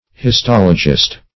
Histologist \His*tol"o*gist\, n.
histologist.mp3